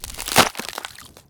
RoleDie.mp3